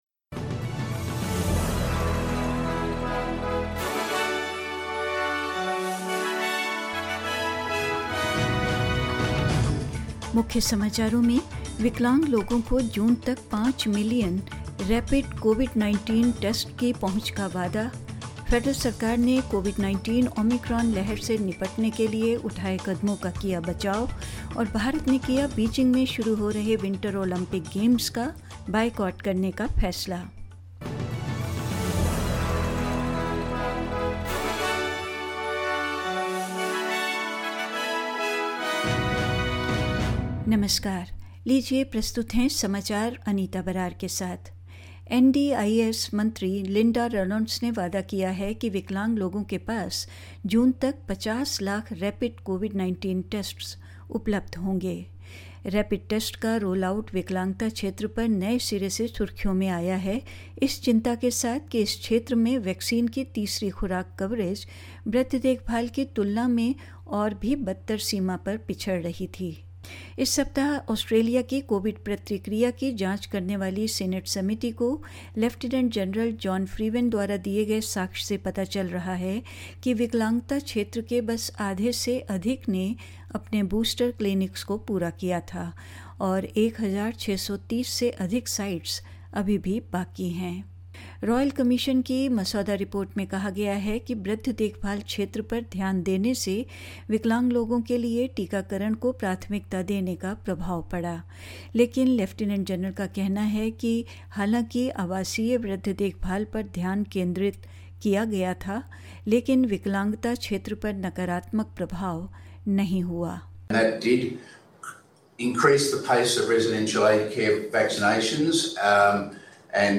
SBS Hindi News 04 February 2022: The Federal Government once again defended its handling of the COVID -19 crisis
In this latest SBS Hindi bulletin: People living with disabilities have been promised access to five million COVID19 tests by June amid growing concerns within the sector; The federal government has defended its handling of the COVID19 Omicron wave ahead of Parliament's return next week; India announced a diplomatic boycott of the Beijing Winter Olympics and more news.